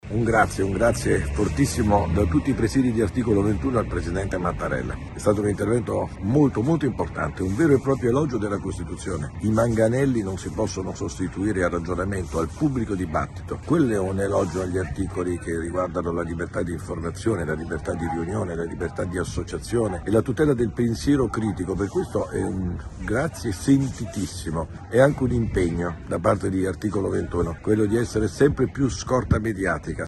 Apprezzamento da diverse organizzazioni della società civile per le parole del Presidente della Repubblica Sergio Mattarella dopo i fatti di Pisa e Firenze, dove la polizia ha caricato gli studenti in corteo. Ascoltiamo Giuseppe Giulietti, portavoce di Articolo 21